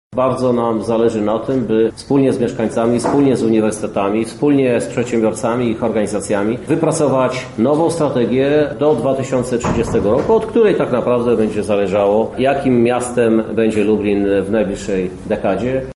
Strategia jest głównym warunkiem skutecznego rozwiązywania problemów mieszkańców i dynamicznego rozwoju miasta mówi Prezydent Miasta Lublin Krzysztof Żuk: